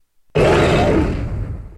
Grito de Pyroar.ogg
) Categoría:Pyroar Categoría:Gritos de Pokémon de la sexta generación No puedes sobrescribir este archivo.
Grito_de_Pyroar.ogg.mp3